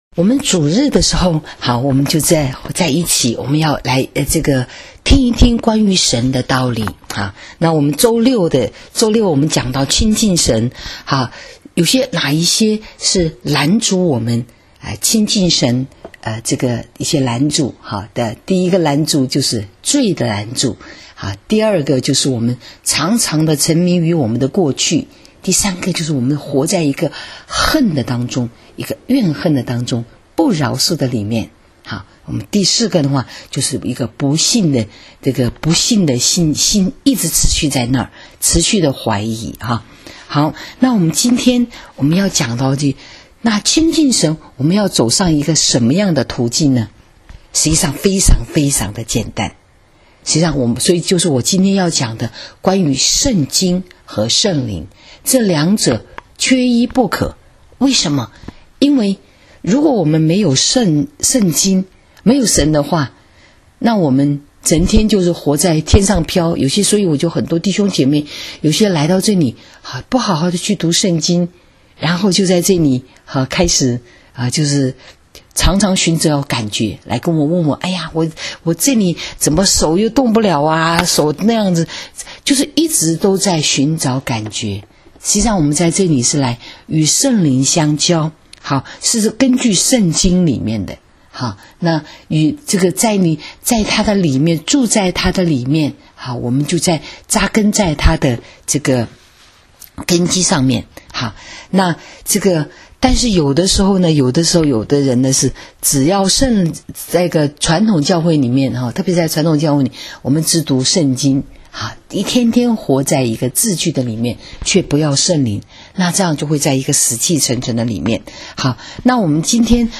【主日信息】亲近神（2） —圣经与圣灵 （7-14-19）